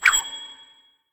nut_fly_04.ogg